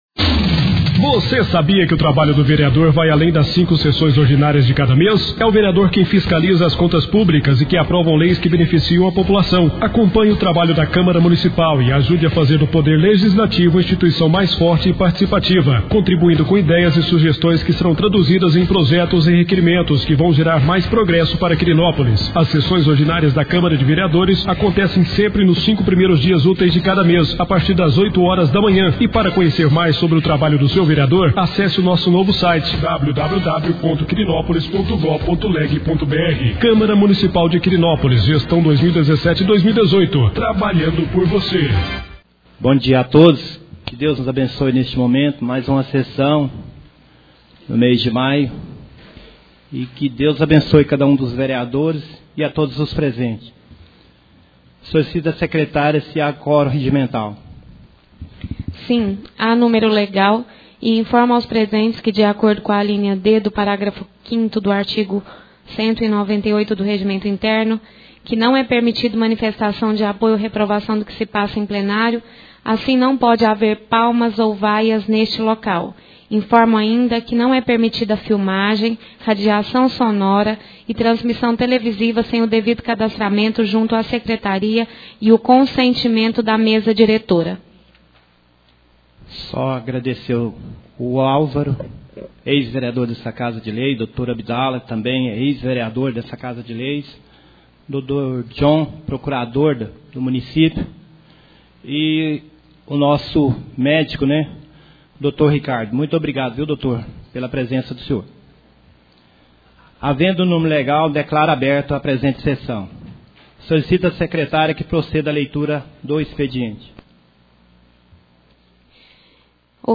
2ª Sessão ordinária do mês de Maio 2017